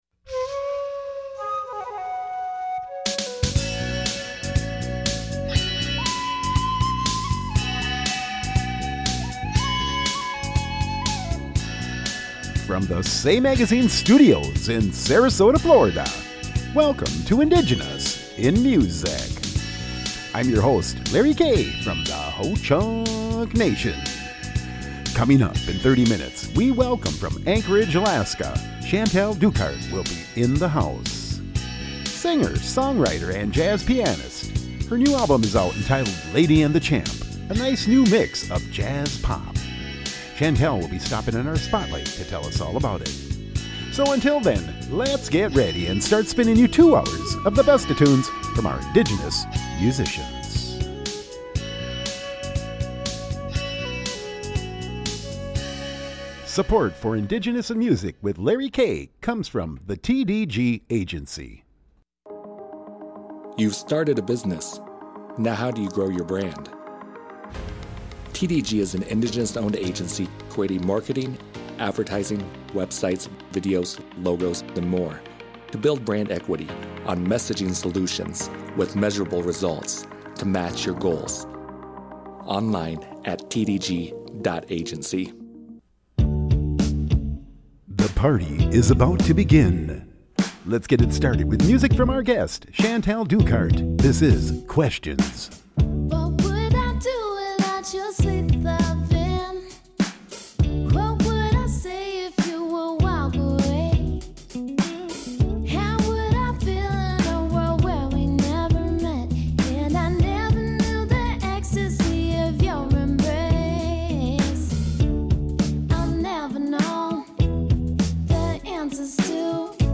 Hr 1 Subtitle: 2 hours of new and original music from our Indigenous, Native, Mexican, Aboriginal musicians of the western hemisphere.